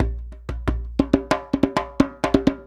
90 JEMBE5.wav